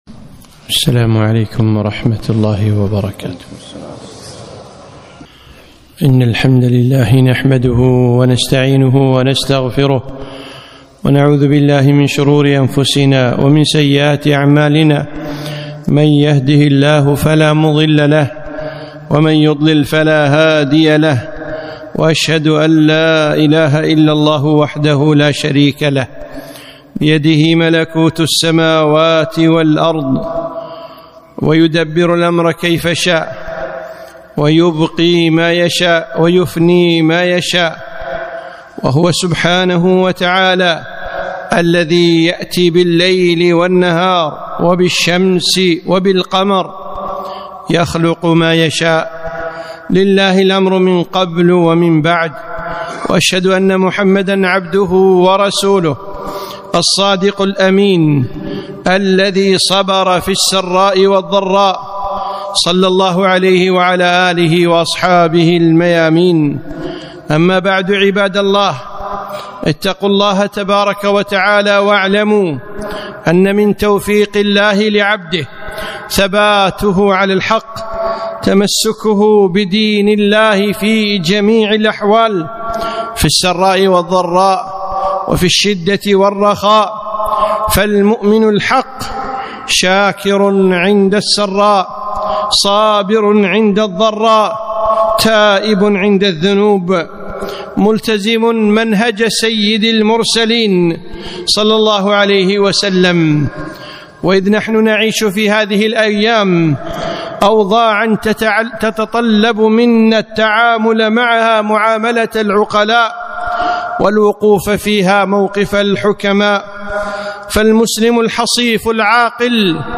خطبة - الحفيظ هو الله